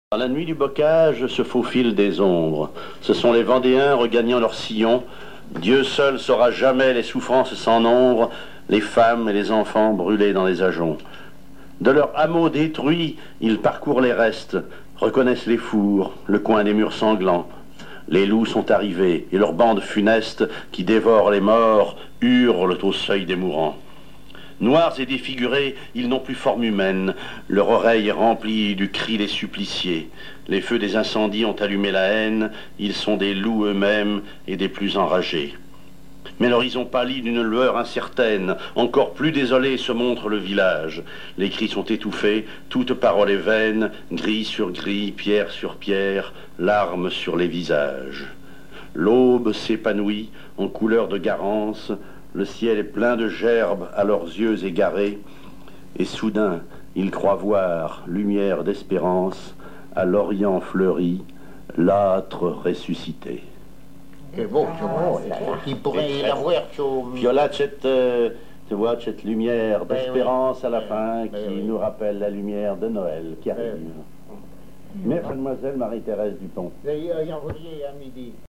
Genre poésie
émission La fin de la Rabinaïe sur Alouette
Catégorie Récit